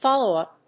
アクセントfóllow‐ùp